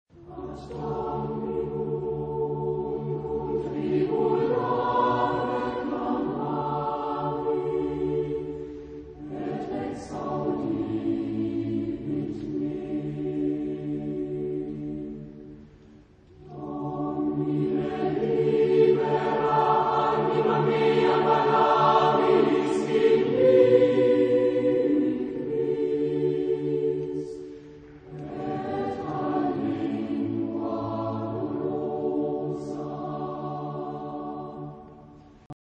Genre-Style-Forme : Sacré ; Hymne (sacré) ; Anthem ; Psaume
Type de choeur : SATB  (4 voix mixtes )
Tonalité : ré majeur
interprété par Knabenchor Reutlingen
Réf. discographique : 7. Deutscher Chorwettbewerb 2006 Kiel